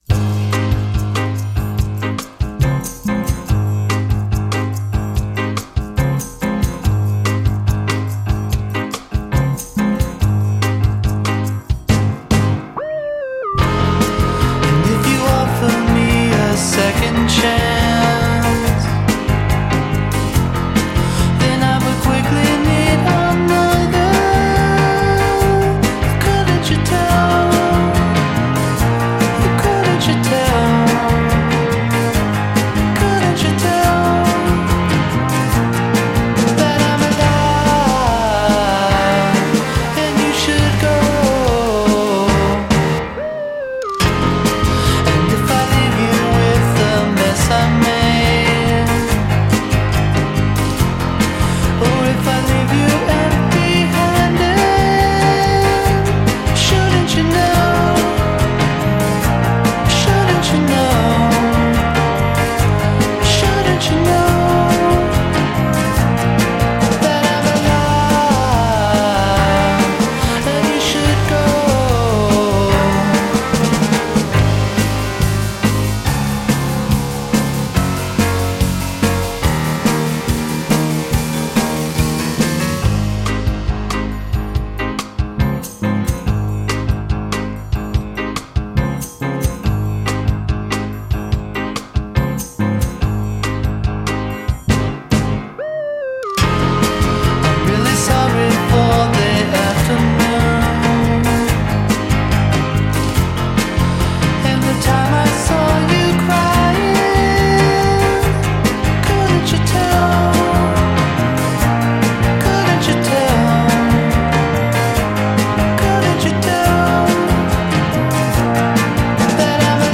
If you combine the piano pounding sounds of Ben Folds
is a Bacharach-flavored lounge-y bossa nova (in a good way)